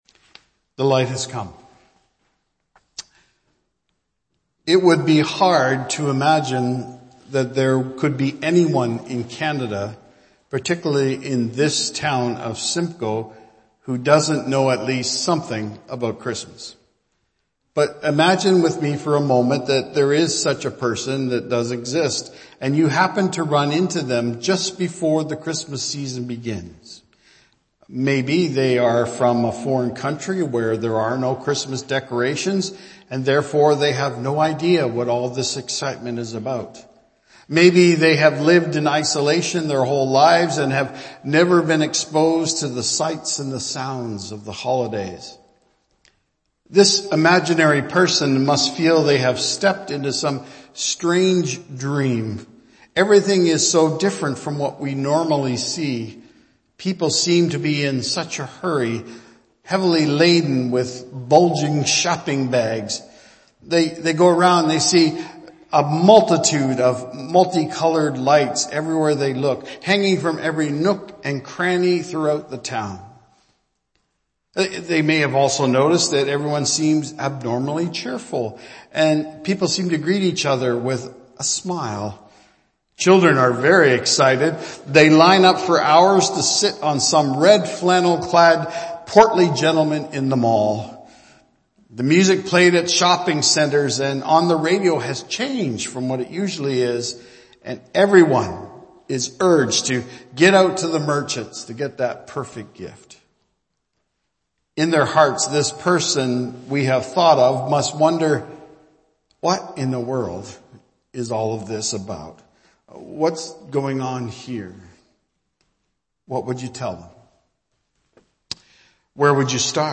Archived Sermons
Christmas Eve